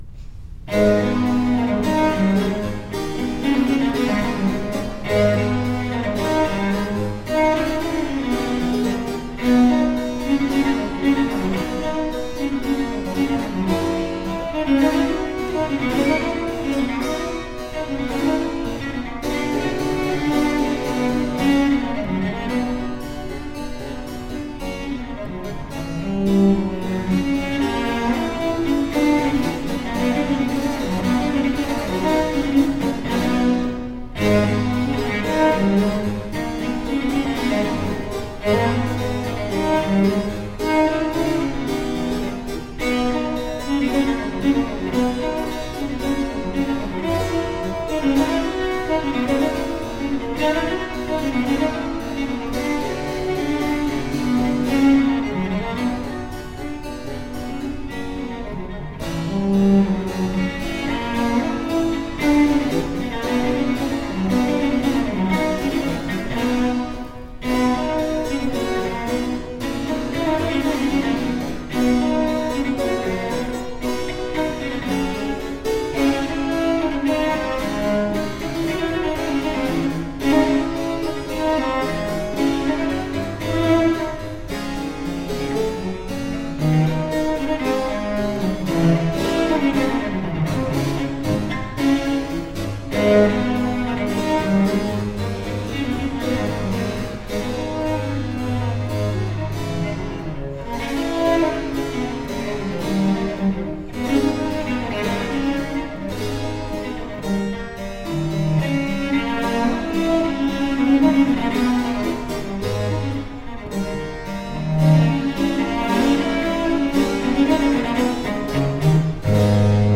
Classical, Chamber Music, Baroque, Instrumental, Cello